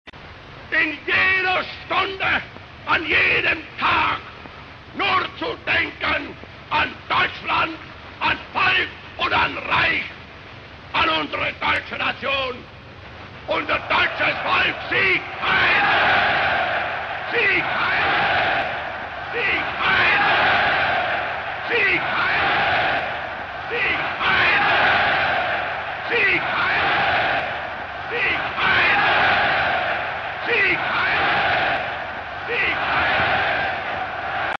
• Категория: Голоса детей (Детские звуки)
• Качество: Высокое